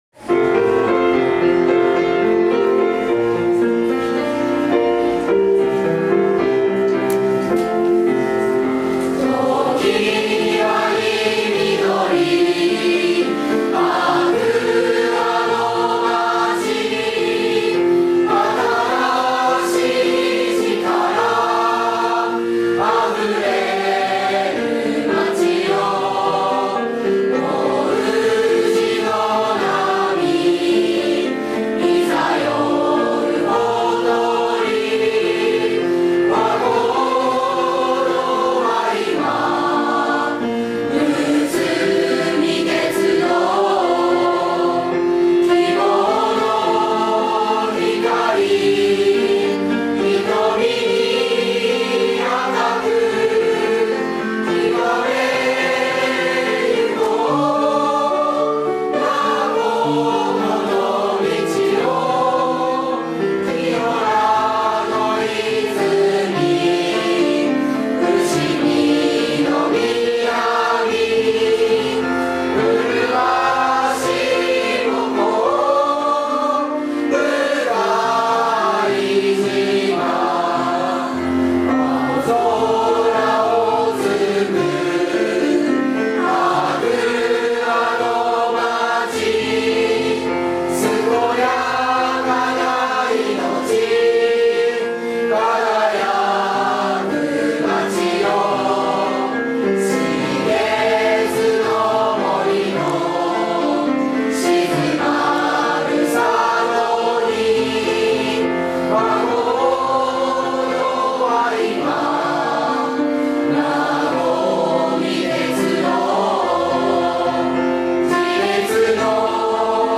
行事では常に歌っている校歌です。